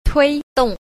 3. 推動 – tuīdòng – thôi động (thúc đẩy, động lực)